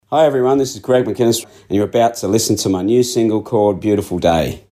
Radio Intro 1